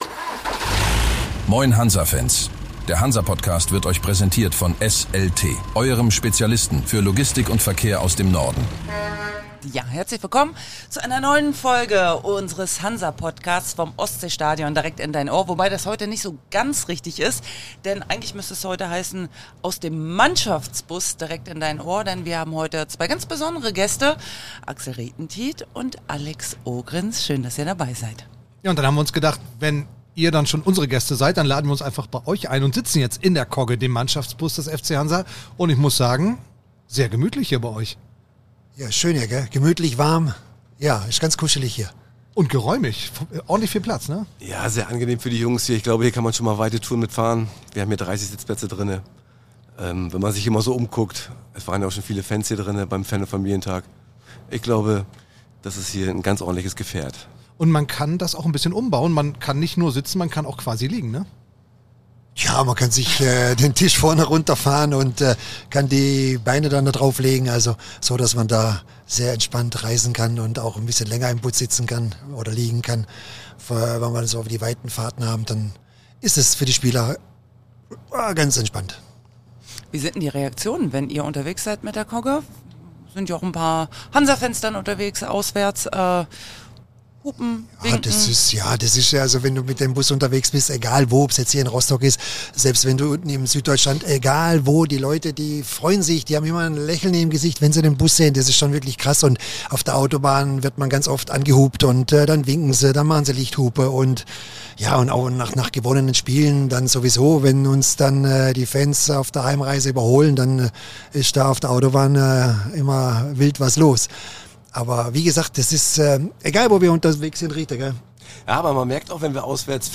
Die aktuelle Ausgabe kommt dieses Mal übrigens - ganz stilecht - aus dem Mannschaftsbus selbst, in dem wir es uns im hinteren Teil gemütlich gemacht haben.